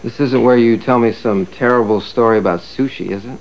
Sound Effects for Windows
1 channel